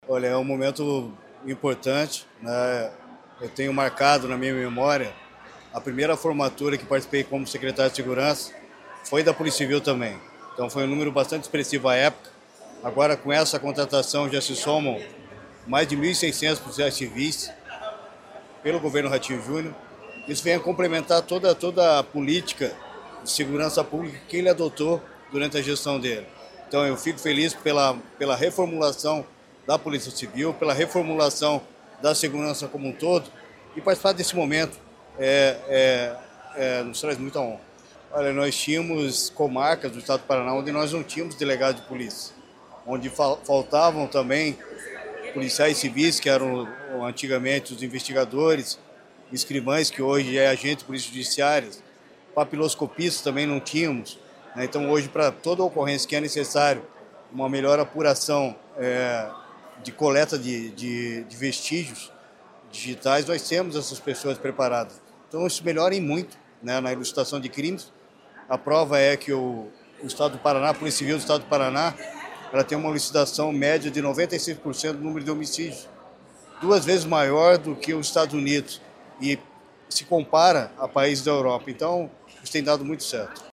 Sonora do secretário da Segurança Pública, Hudson Leôncio Teixeira, sobre a formatura de 555 novos integrantes da PCPR